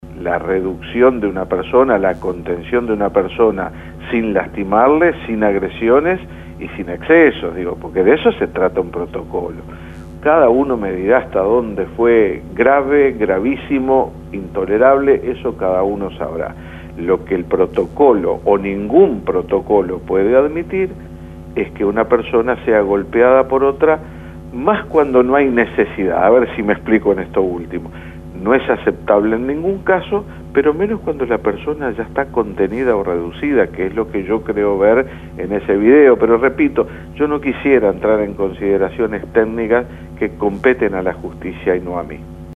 Bellomo expresó, en diálogo con El Espectador, que desde que asumió, hace alrededor de tres meses, todas las semanas se han iniciado investigaciones administrativas o se han aplicado sanciones por hechos similares a los que se pueden ver en el video difundido este miércoles del Hogar Ceprili